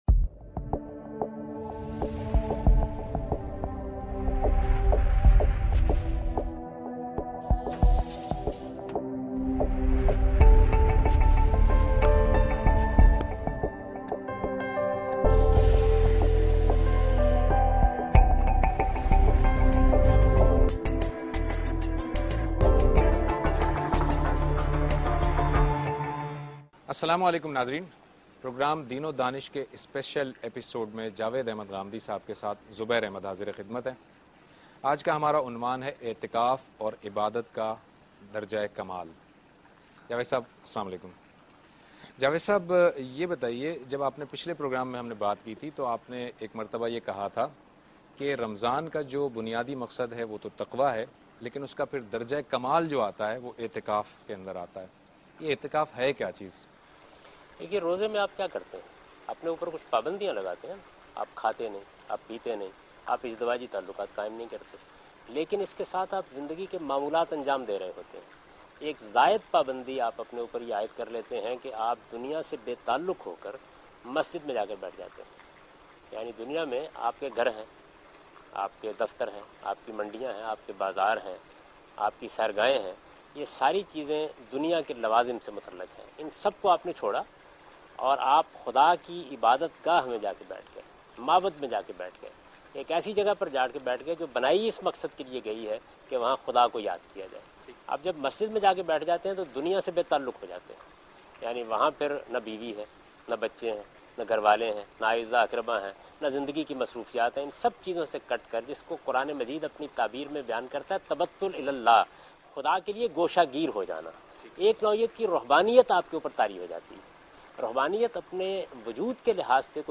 Category: TV Programs / Dunya News / Deen-o-Daanish / Questions_Answers /
دنیا ٹی وی کے پروگرام دین ودانش میں جاوید احمد غامدی اعتکاف اور عقیدت کے سربراہی کے متعلق گفتگو کر رہے ہیں